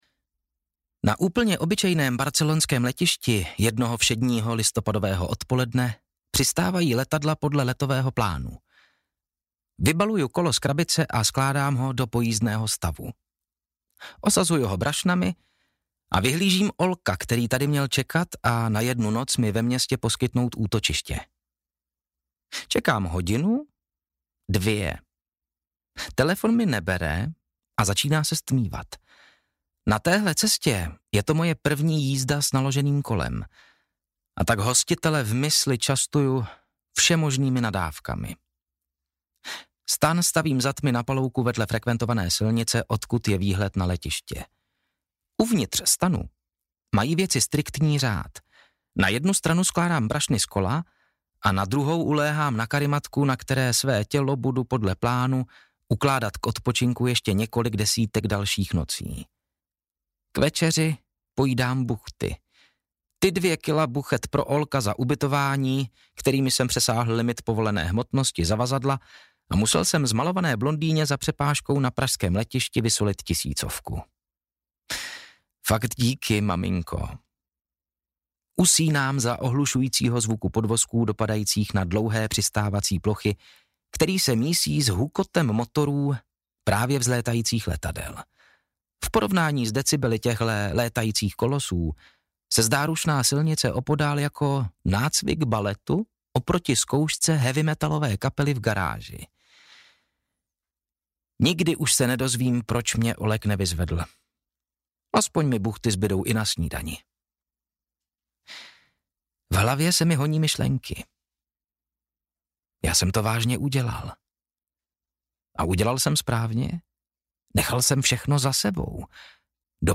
Audiokniha
Čte: Matouš Ruml